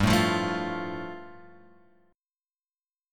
G 7th Suspended 2nd